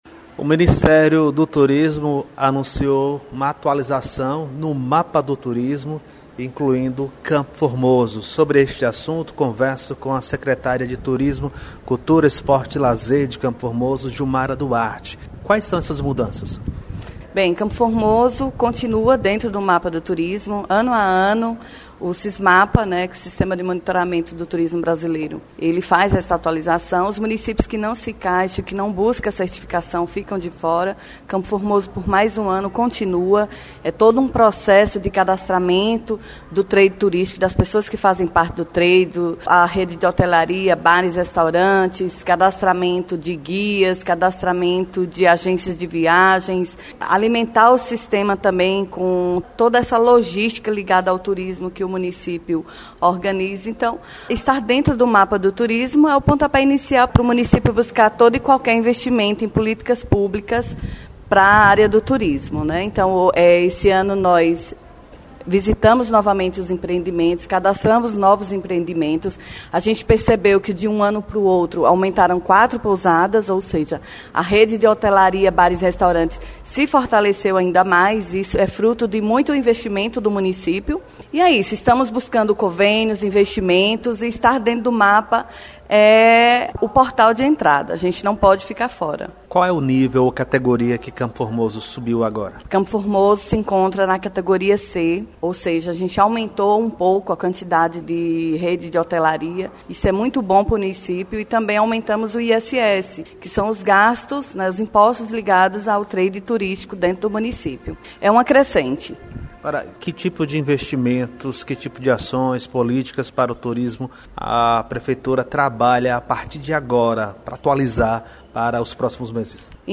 O ministério do turismo anunciou uma atualização no mapa do turismo incluindo Campo Formoso, a secretária de Turismo cultura esporte e lazer de Gilmara Duarte explica